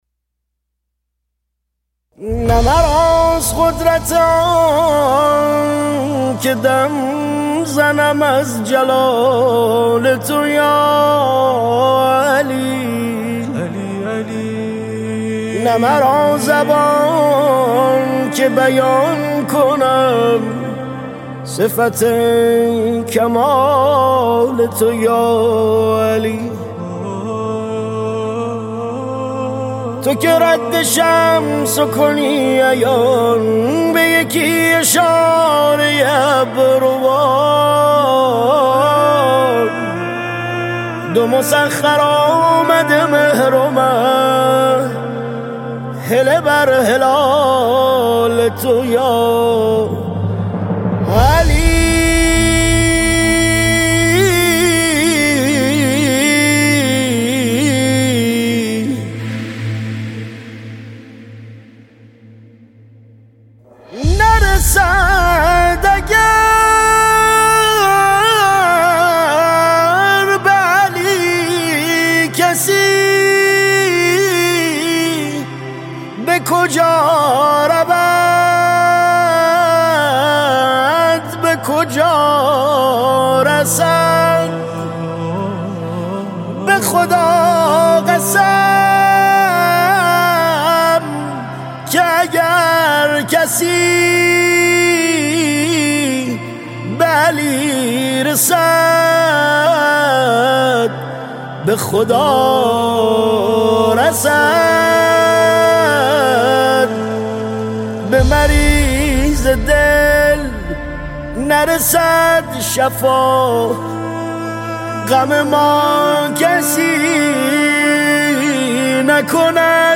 مذهبی
مداحی استودیویی